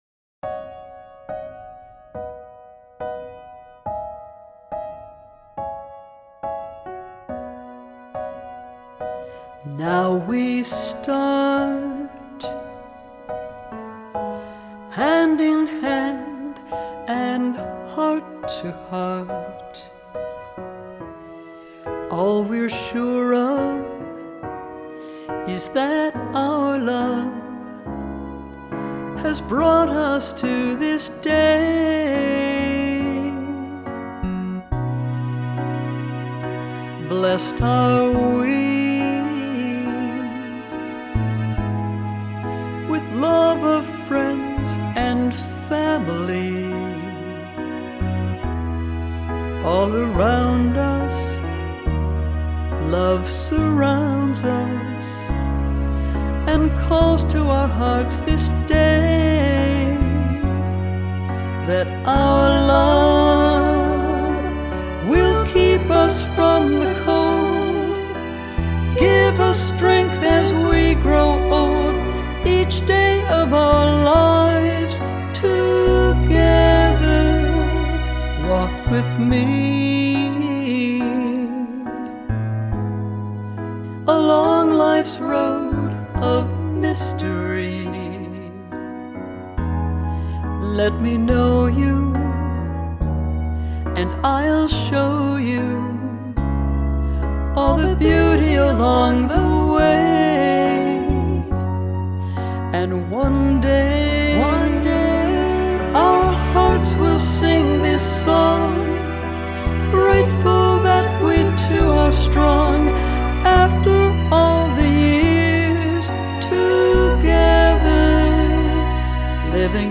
A Wedding Song
sweet voice